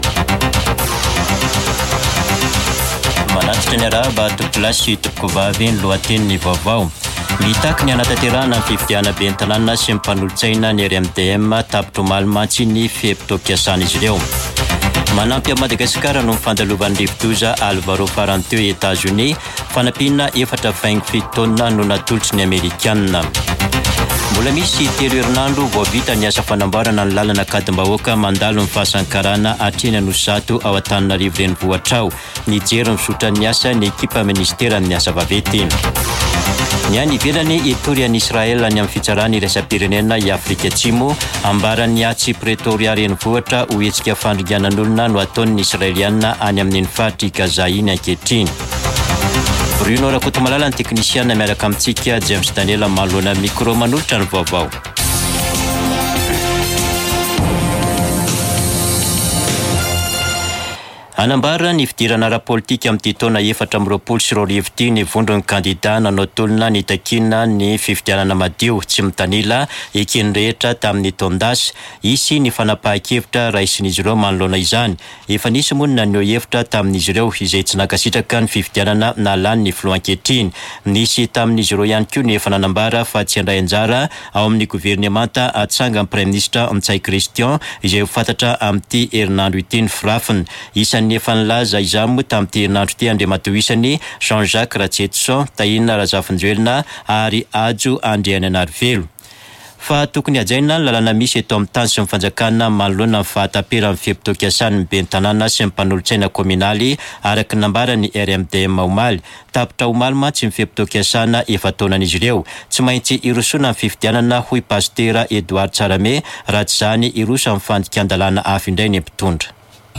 [Vaovao antoandro] Alakamisy 11 janoary 2024